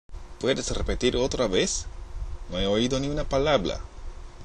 今回は、出だしを「Puedes」と聞いたか「Puede」と聞いたかで、
ちょっと発音も良くなかったようで、反省です＾＾；